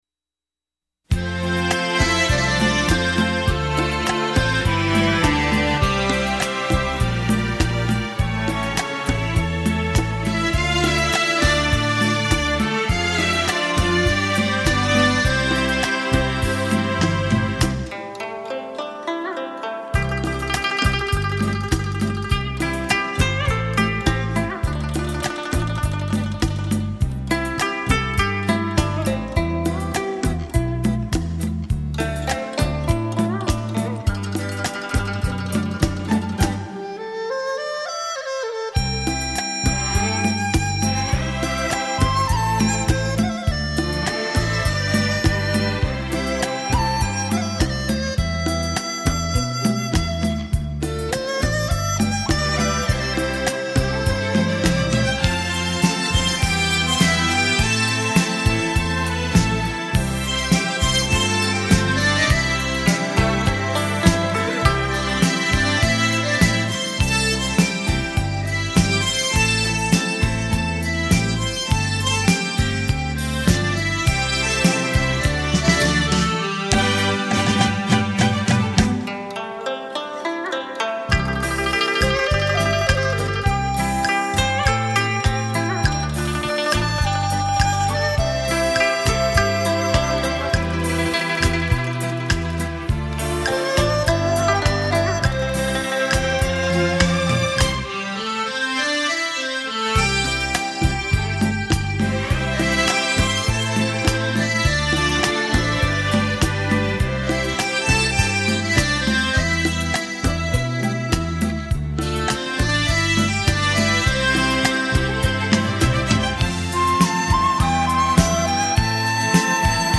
老歌演奏专辑